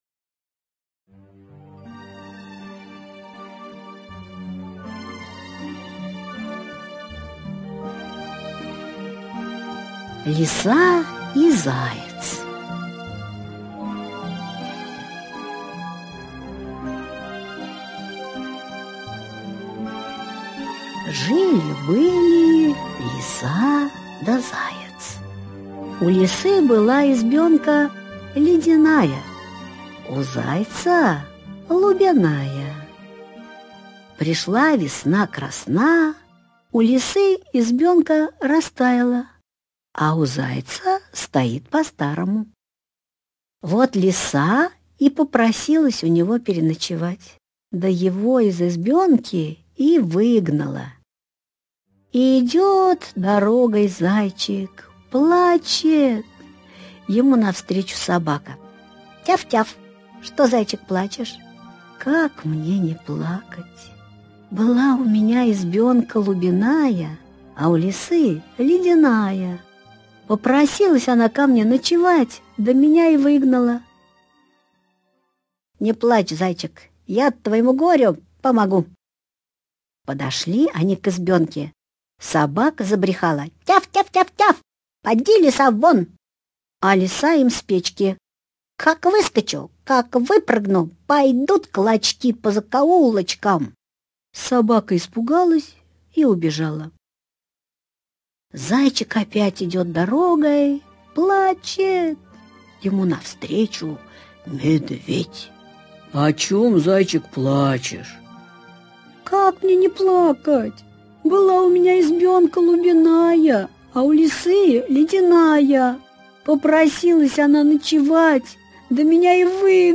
Аудиосказка «Зайкина избушка»
Русские народные аудиосказки